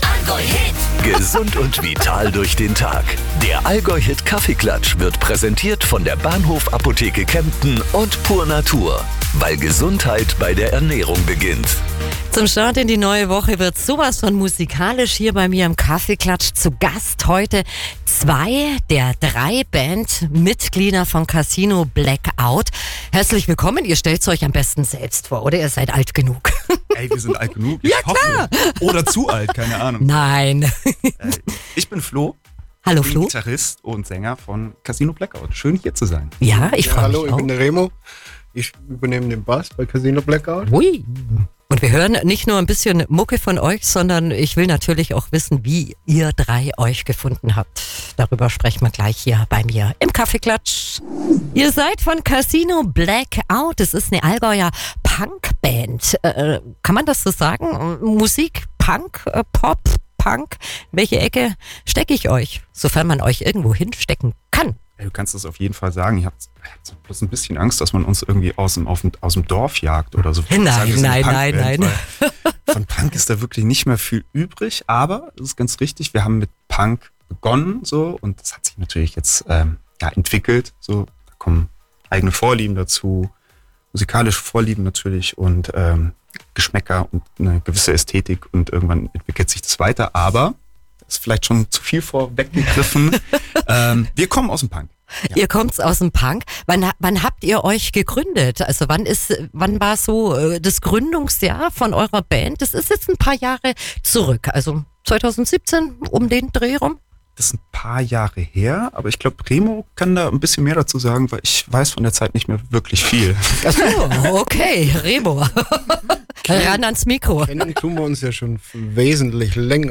Studio Talk